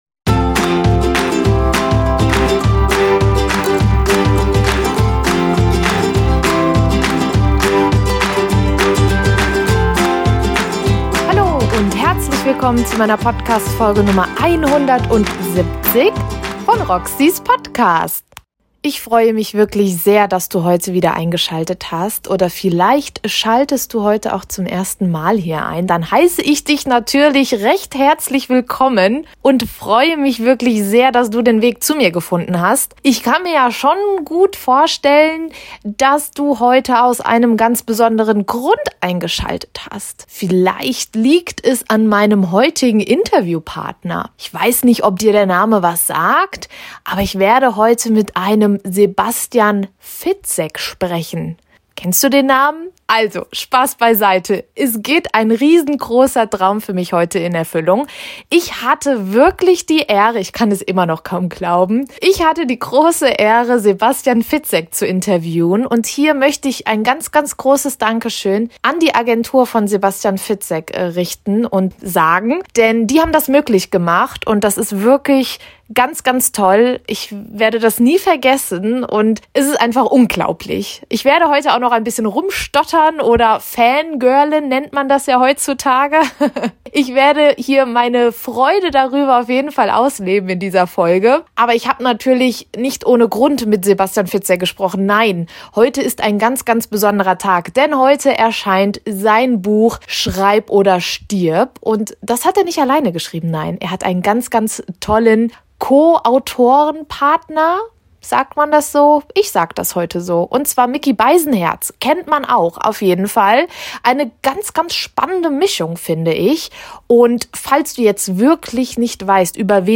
Ich spreche heute mit Sebastian Fitzek persönlich über das Buch.